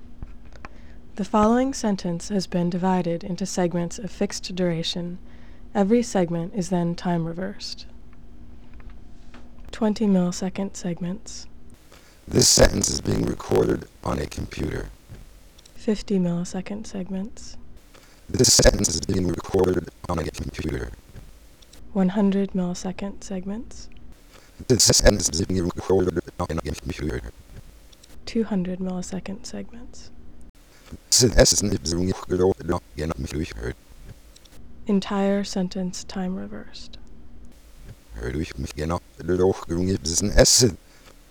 Reversed_Speech_Demo.wav